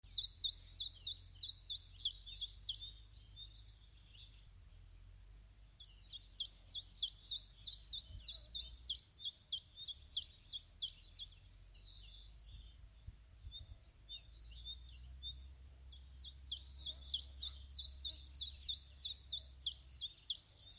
Chiffchaff, Phylloscopus collybita
StatusSinging male in breeding season